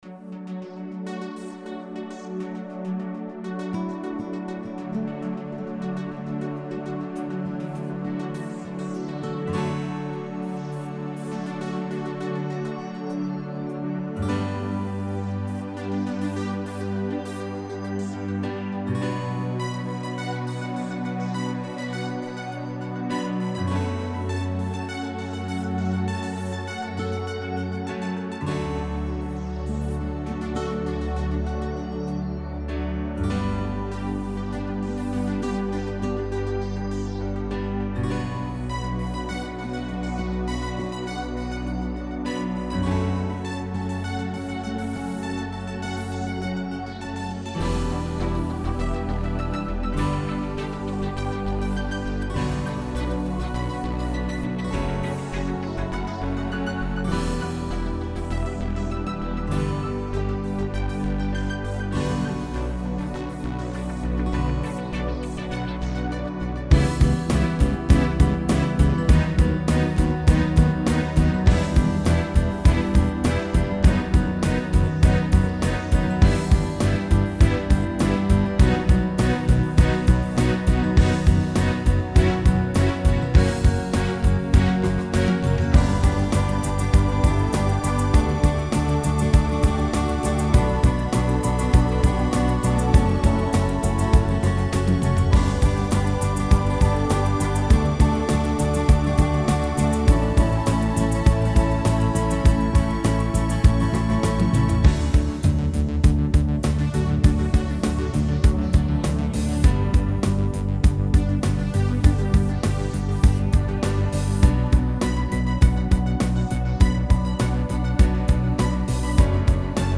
backing track
This one is all OASYS.